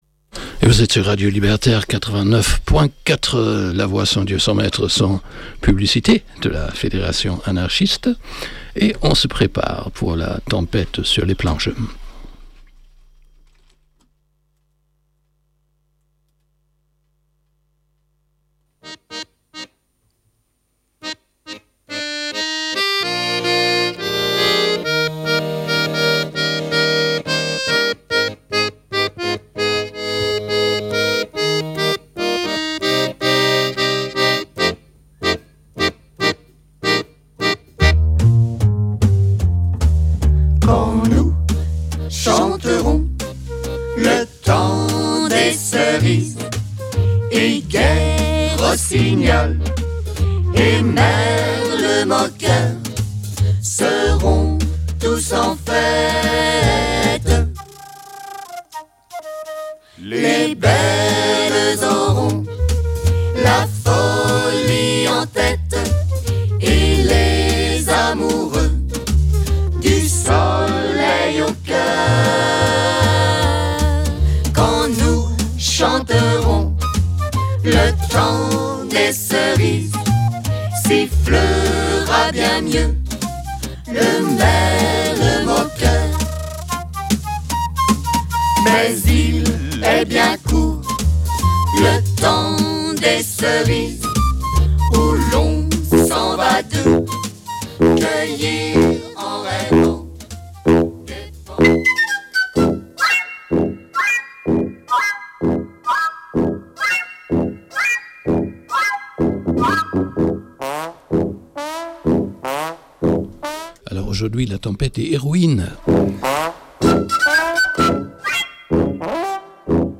Voici donc quelques‐uns de ces courtes pièces surréalistes, parfois métaphysiques, souvent très drôles quand d’autres frôlent le tragique, toujours dans une écriture poétique, lyrique et souvent décalée.